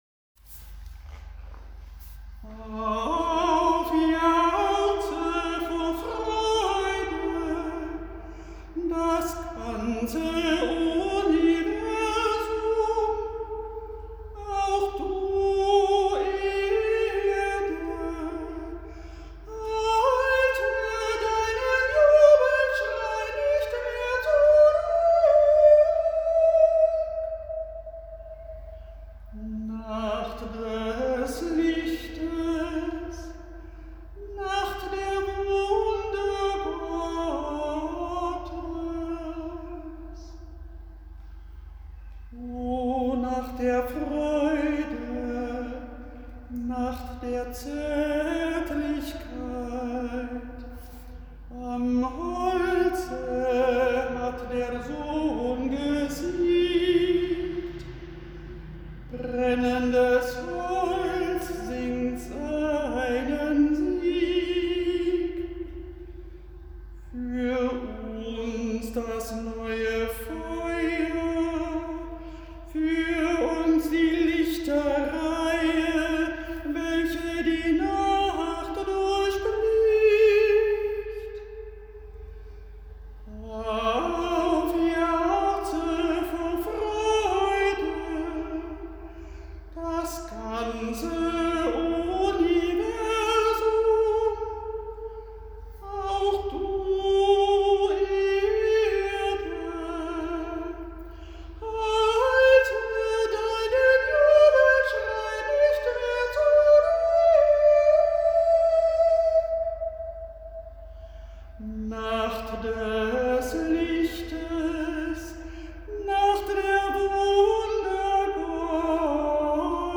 • Exultet – aus der Osternacht-Liturgie
Gesang